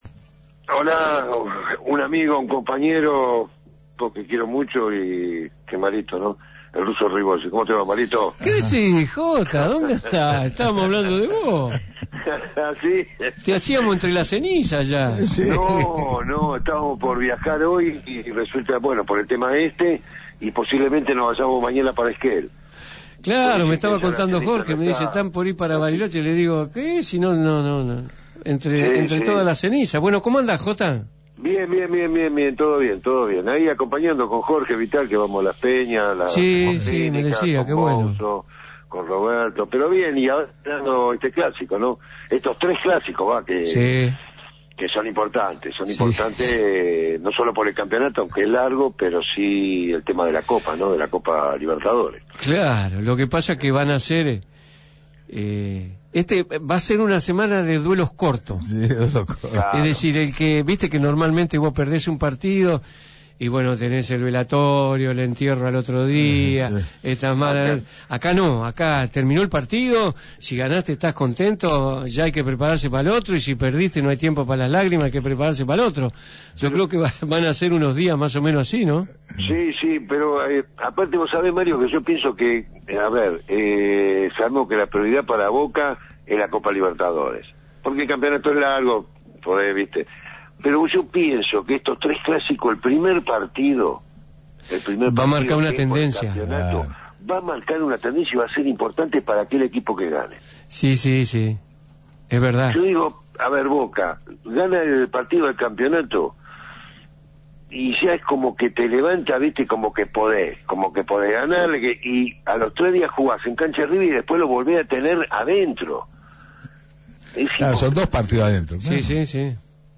Invitado a Glorias Xeneizes, programa de la Mutual de Ex Jugadores del Club Atlético Boca Juniors, Mario Nicasio Zanabria visitó Radio Gráfica.
Lleno de anécdotas y mucho color, como el momento que lo sorprendieron al gran Mario con una comunicación telefónica con el Ruso Ribolzi.